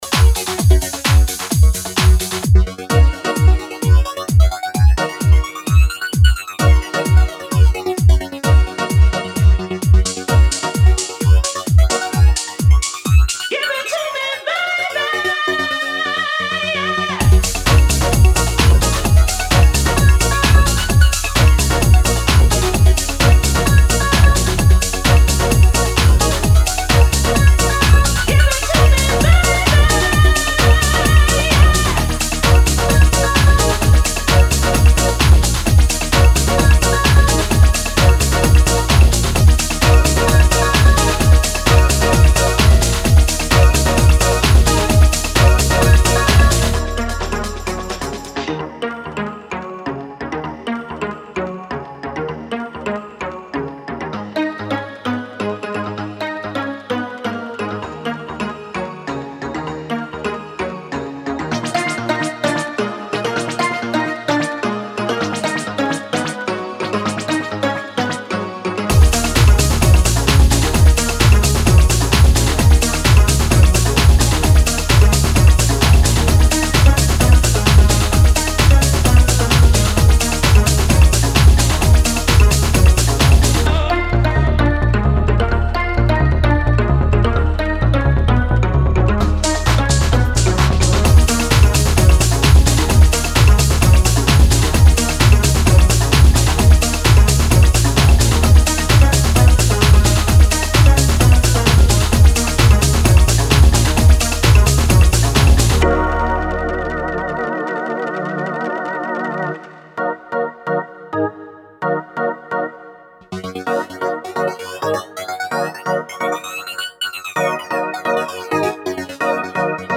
極彩色で陶酔的なピークタイム・チューンを展開。